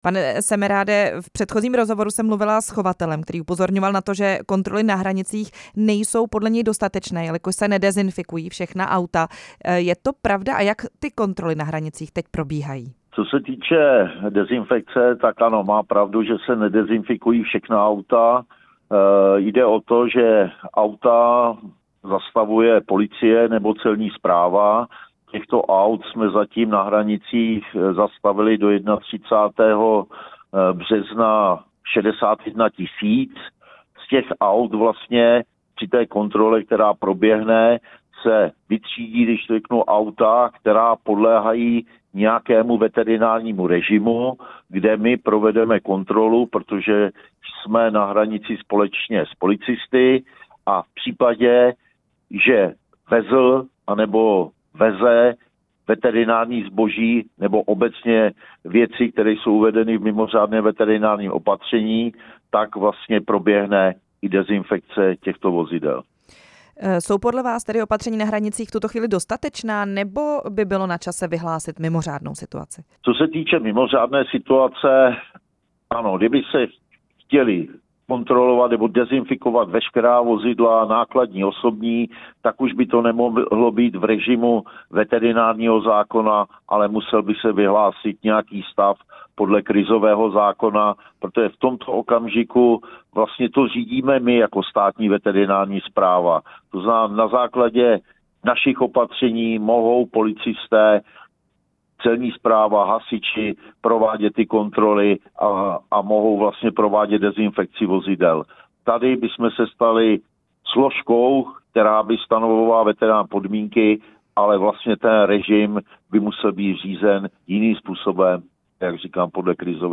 Rozhovor s ústředním ředitelem Státní veterinární správy Zbyňkem Semerádem